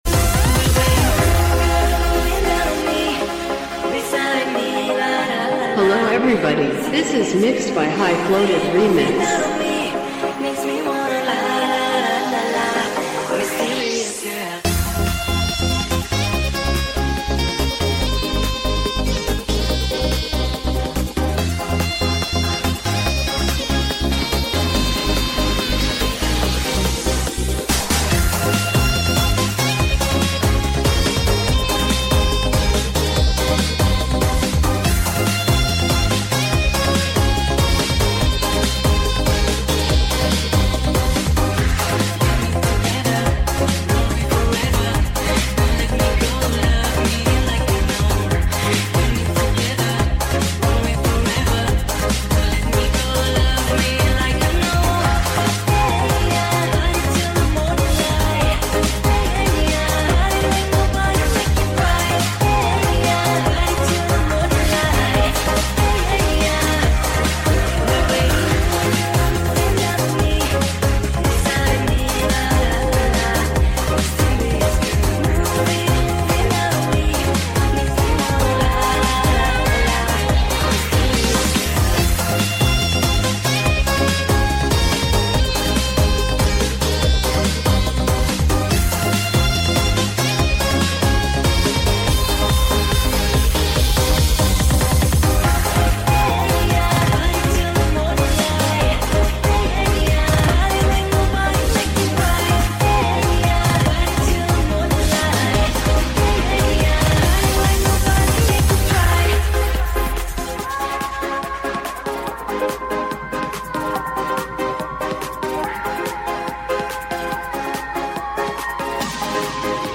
Knalpot sunblue mu brew #54nracingmuffler